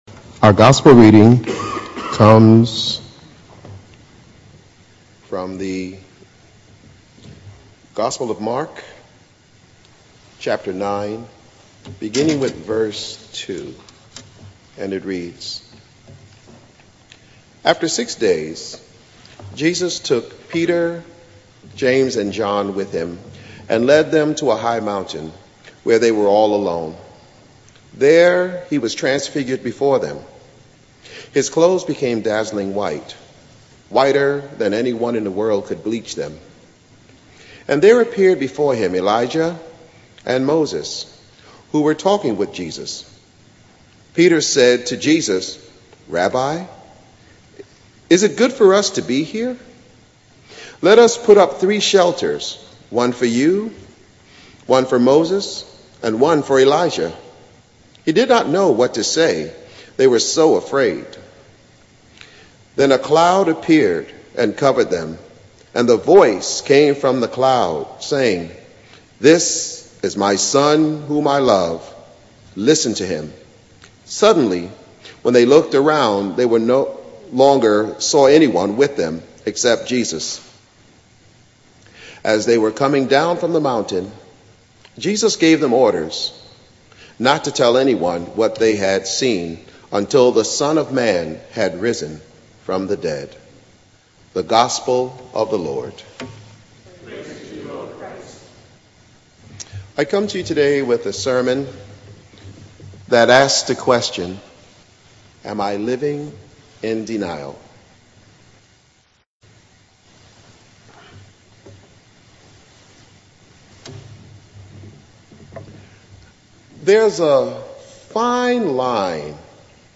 Sermons and Anthems | The Second Reformed Church of Hackensack